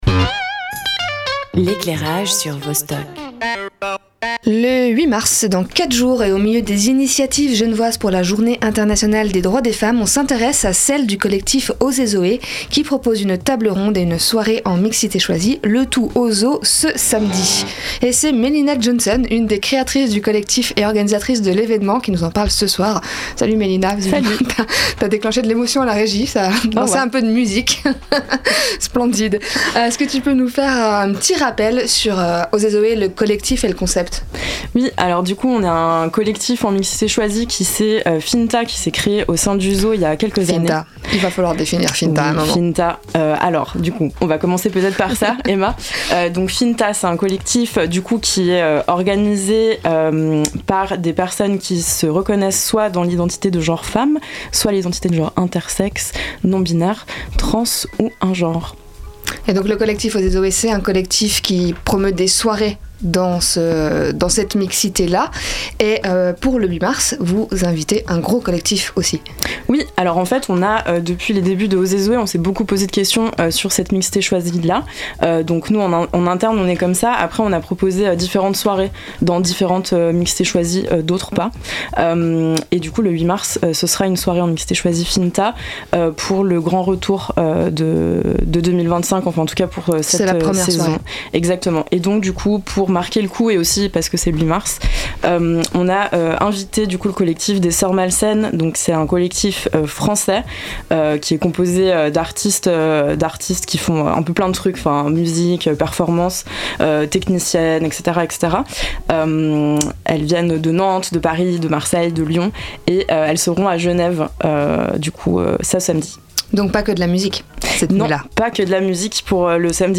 Première diffusion antenne : 5 mars 2025